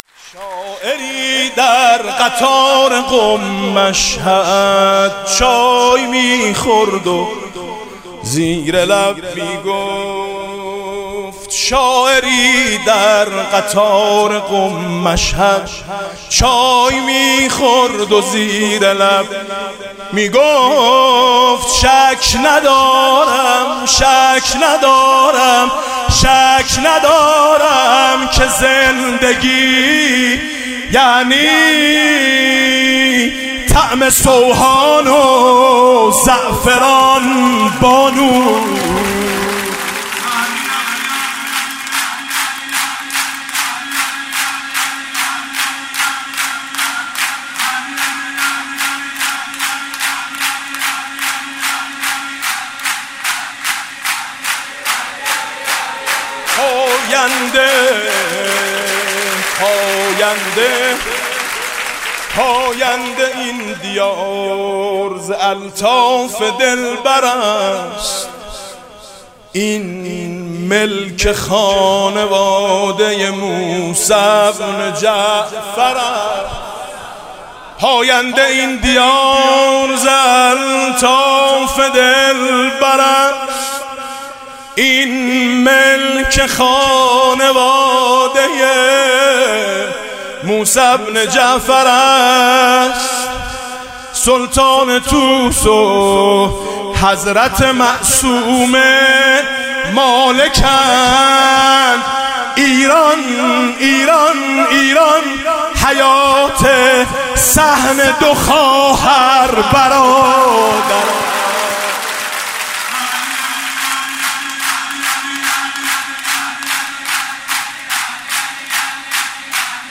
مولودی خوانی میلاد حضرت معصومه (س)/ میثم مطیعی